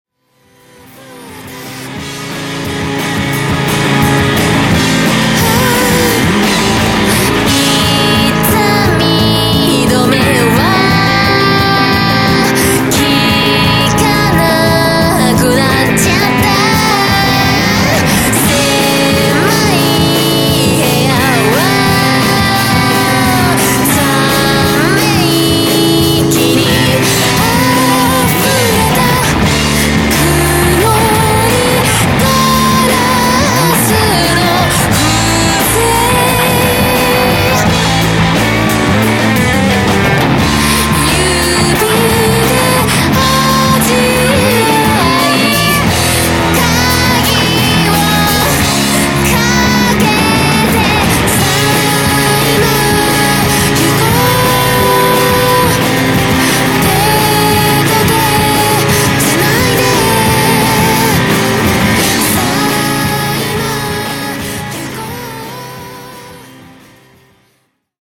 目指すはキャッチー＆ダーク＆ポップ、だそうです。
どの曲もキャッチーでダークでポップです。
何よりボーカルキャラが光ってます。